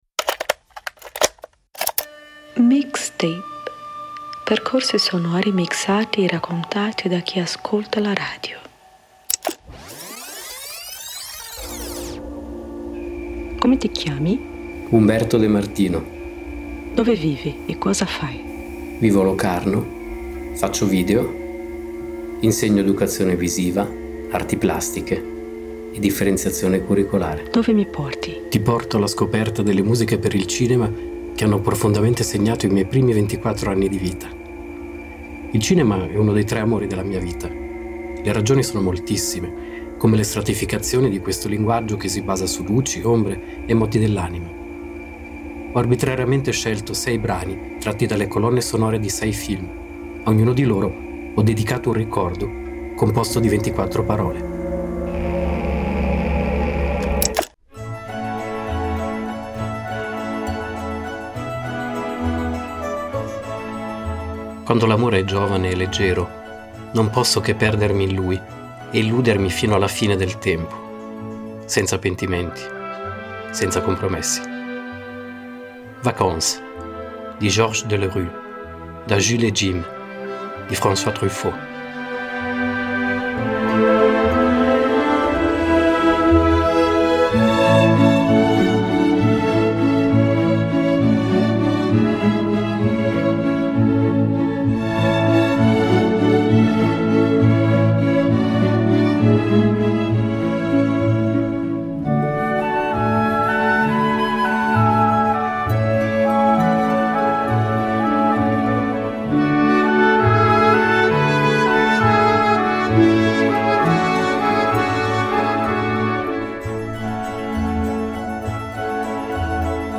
Percorsi sonori mixati e raccontati da chi ascolta la radio